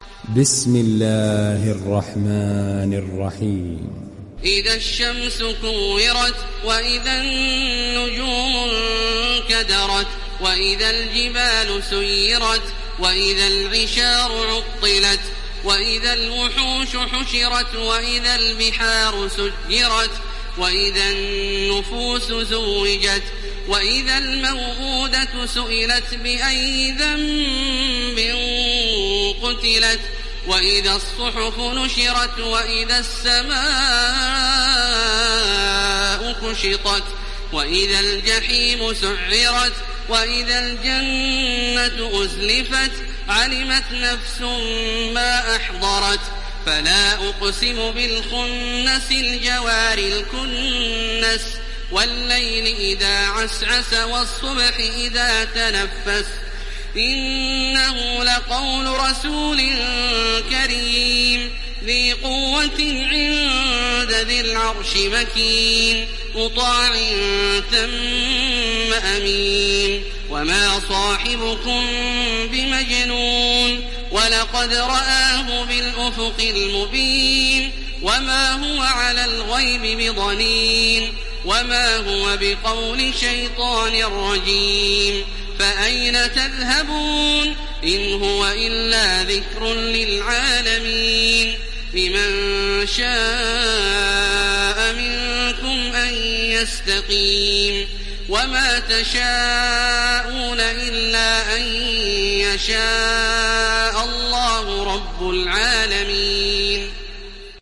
Download Surah At Takwir Taraweeh Makkah 1430